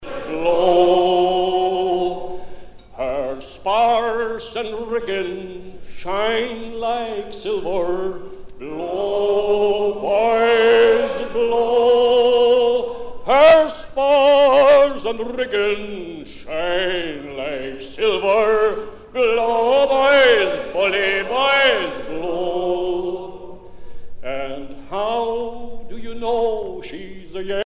Chant de départ par des cap-horniers allemands
Genre strophique
Pièce musicale éditée